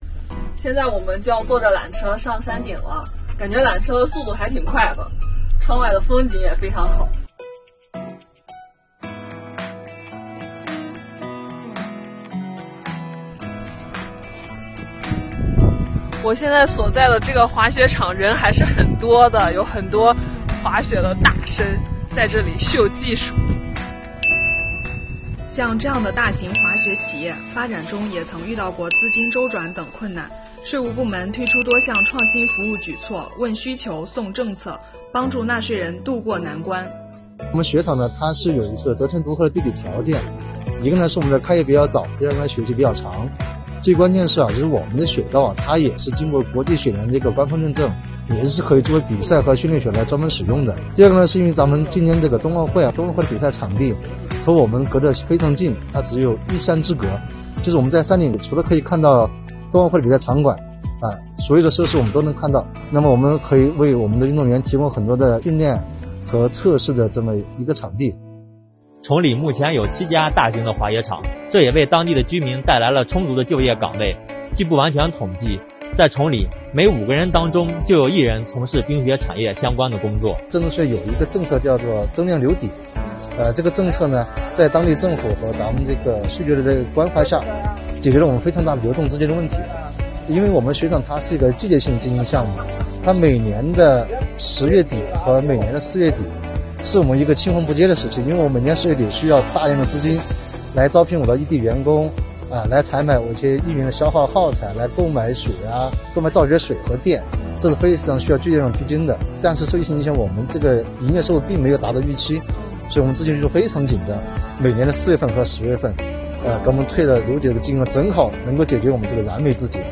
让我们跟随记者，一起来走进崇礼，体验冬奥税收服务～～